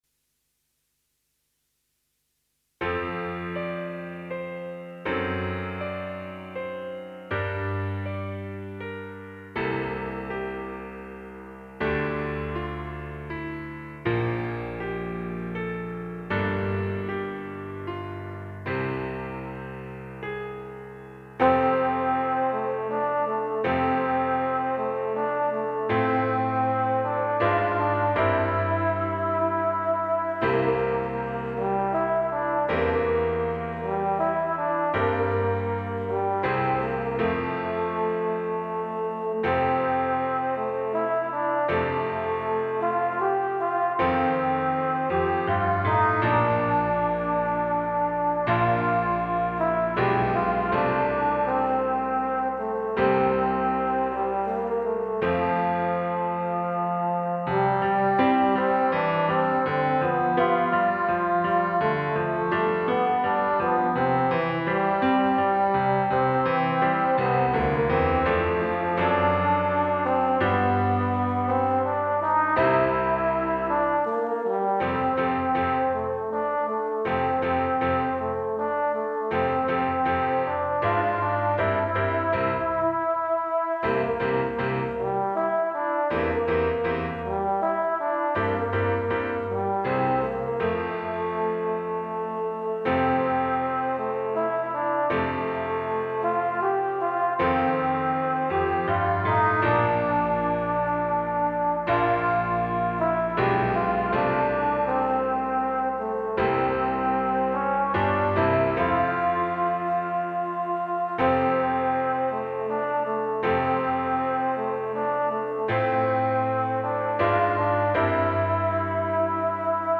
Solo instrument C Bb Eb, Keyboard accompaniment
A beautiful evocative original composition by